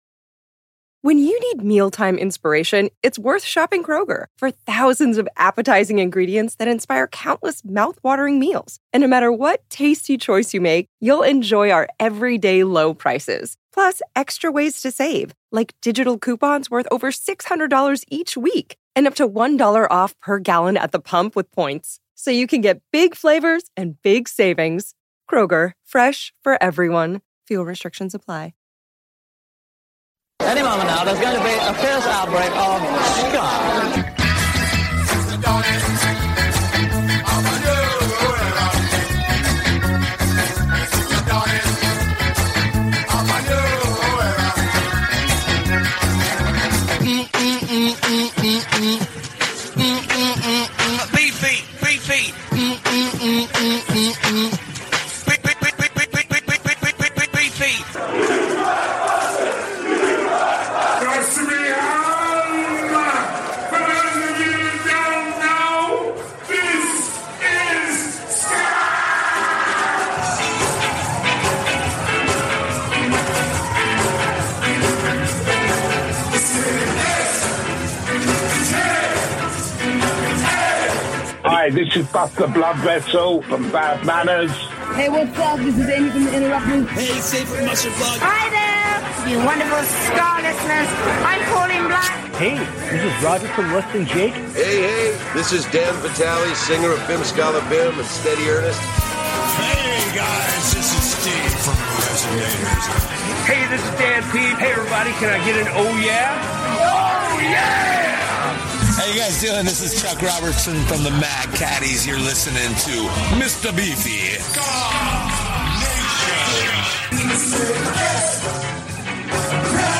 **THE WORLD'S #1 SKA SHOW - SKA NATION RADIO - FOR YOUR LISTENING PLEASURE ** KEEP SPREADING THE GOSPEL OF SKA!